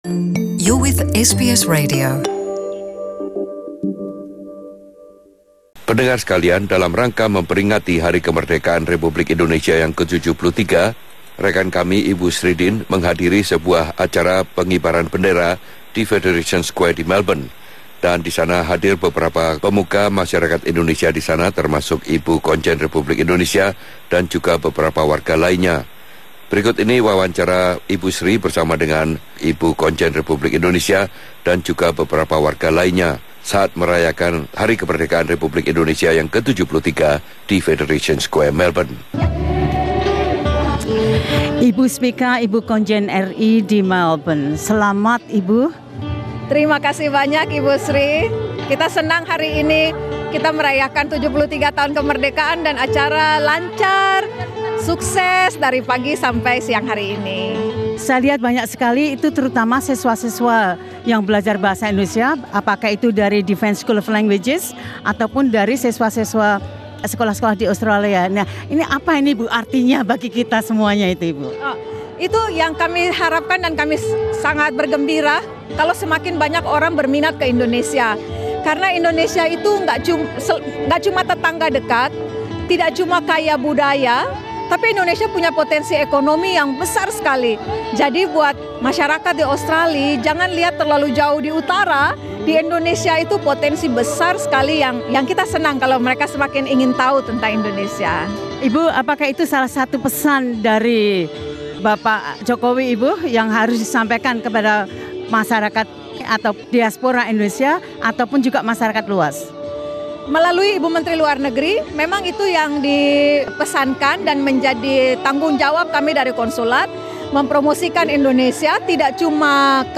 Spica A.Tutuhatunewa, KONJEN RI untuk Victoria dan Tasmania dan beberapa warga komunitas Indonesia menyampaikan pesan-pesan mereka terkait dengan Hari Kemerdekaan.